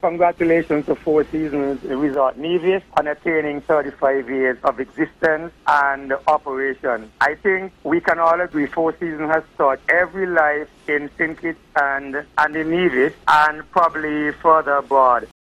That was one of the many calls heard on the February 11th broadcast of “On the Mark”, to celebrate 35 years in operation of the Four Seasons Resort Nevis, West Indies.